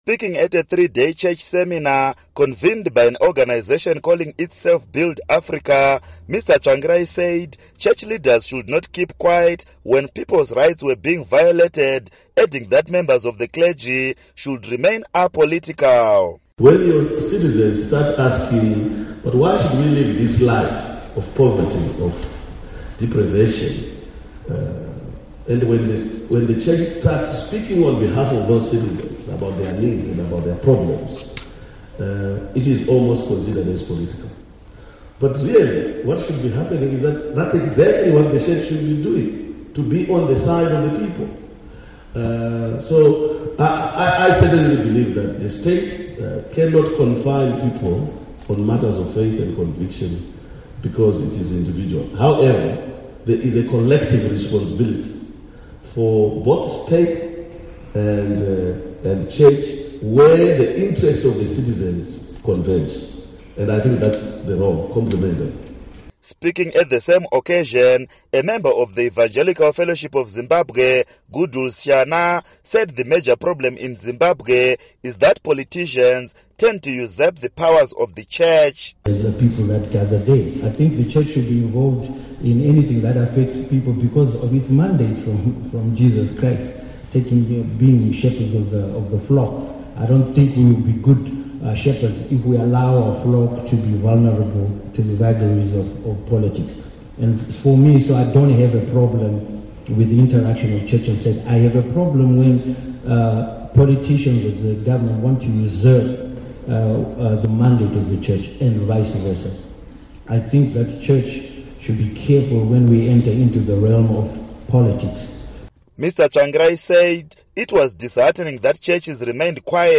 Be sure to tune in Friday for the last interview with Tsvangirai where we pose questions from some of you Studio 7 listeners.